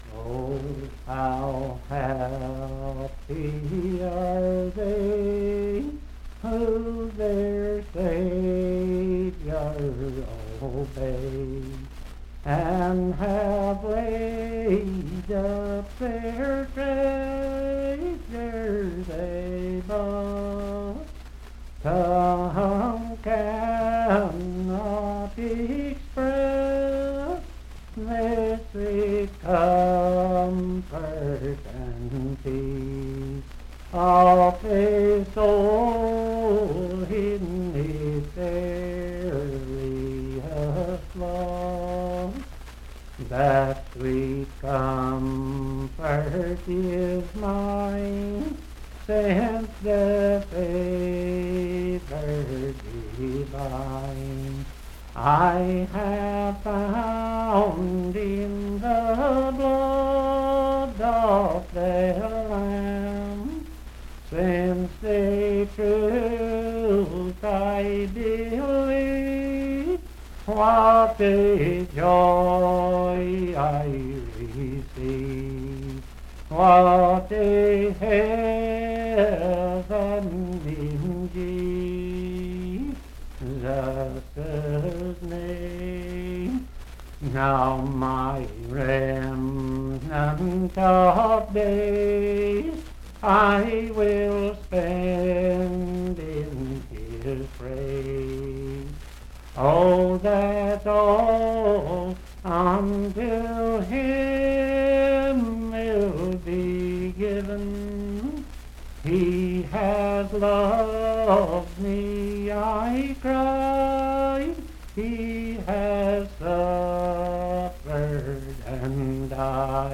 Unaccompanied vocal music
in Dryfork, WV
Verse-refrain 3(4).
Hymns and Spiritual Music
Voice (sung)